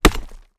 Axe.wav